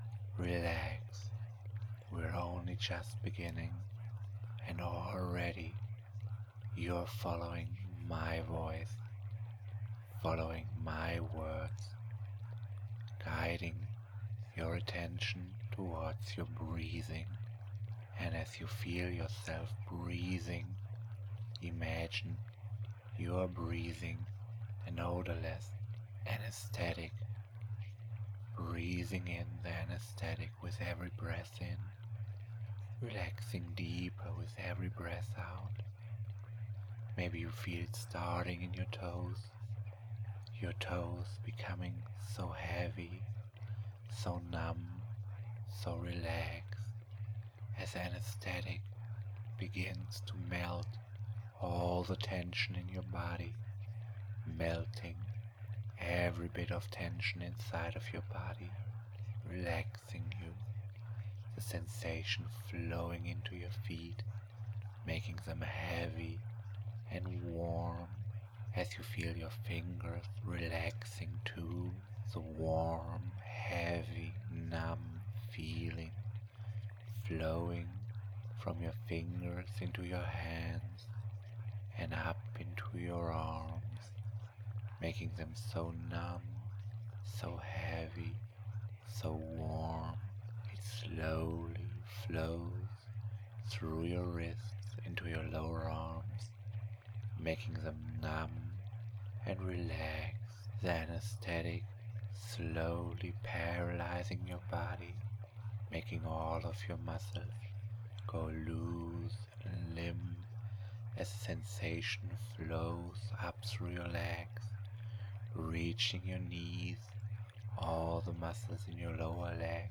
This is a little experimental and loopable mind melt induction.